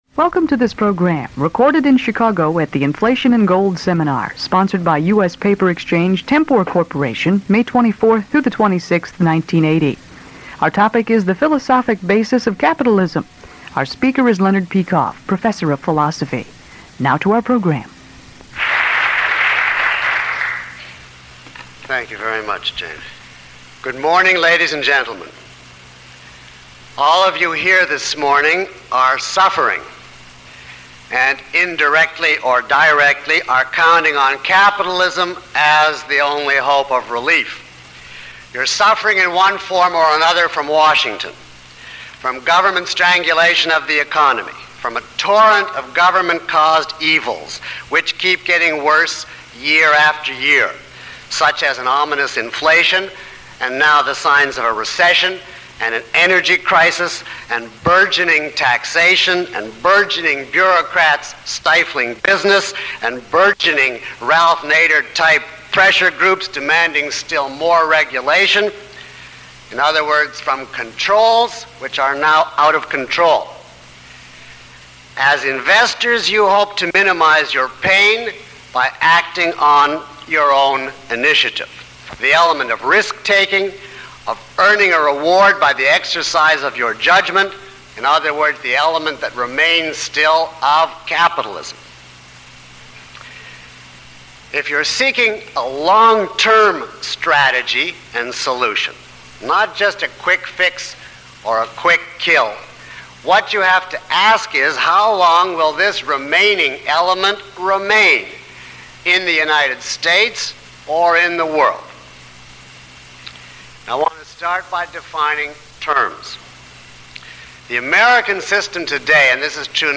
Lecture (MP3) Questions about this audio?